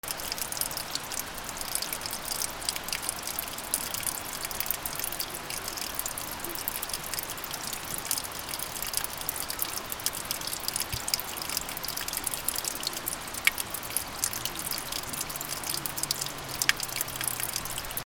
/ B｜環境音(自然) / B-15 ｜水の流れ
1 山道の湧き水
ビチャビチャ 01